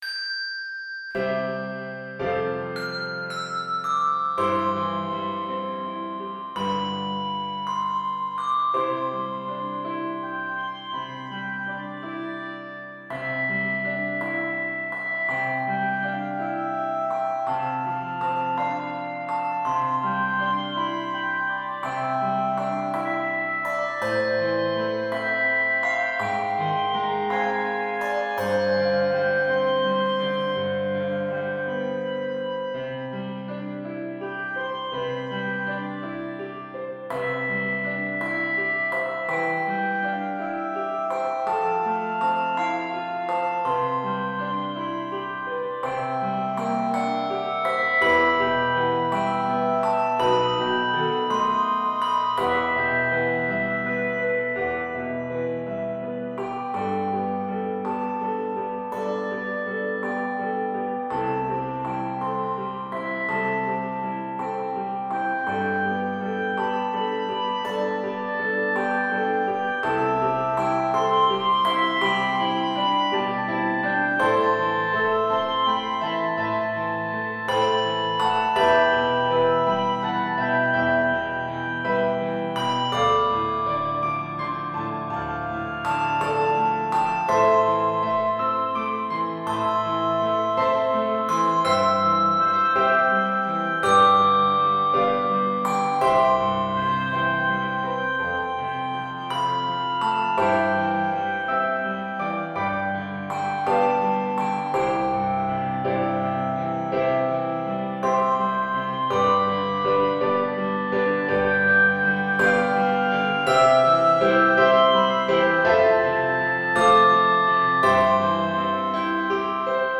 Key of C Major. 125 measures.